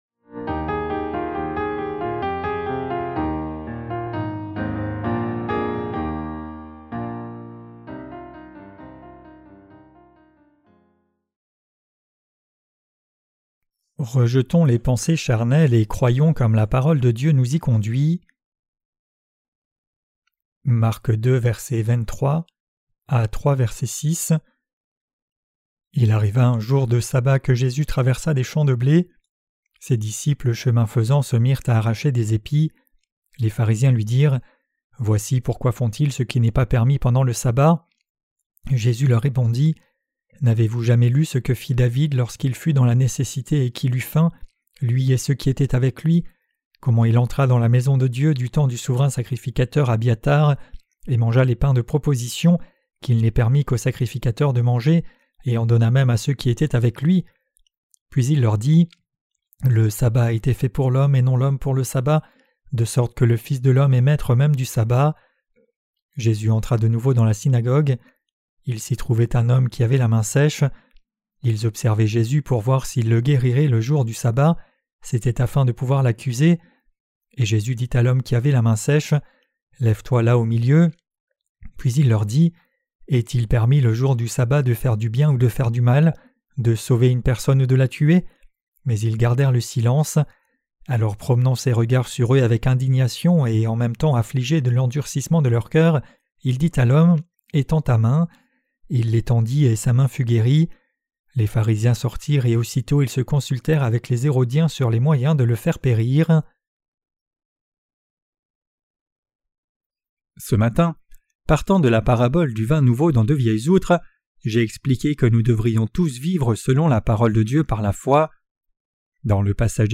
Sermons sur l’Evangile de Marc (Ⅰ) - QUE DEVRIONS-NOUS NOUS EFFORCER DE CROIRE ET PRÊCHER? 3.